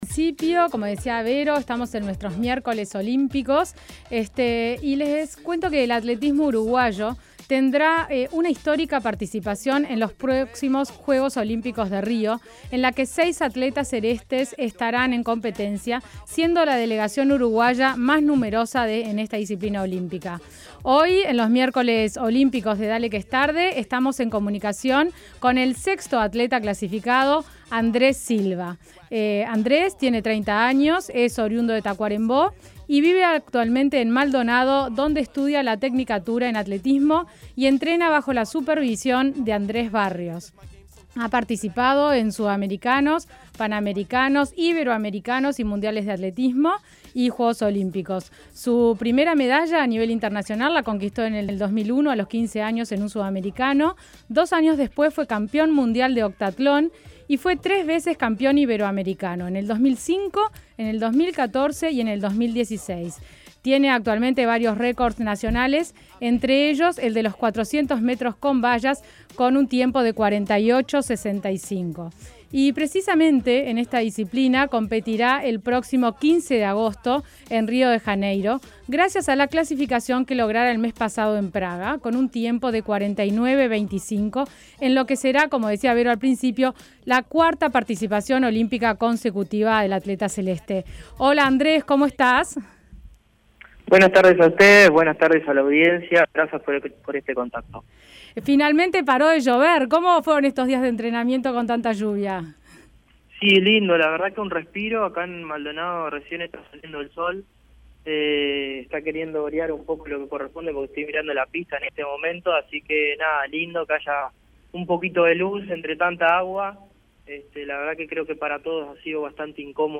Andrés Silva, participa por cuarta vez en un evento de los Juegos Olímpicos. En una charla con Dale Que Es Tarde, cuenta que su objetivo es mejorar el tiempo logrado en las Olimpíadas de Londres.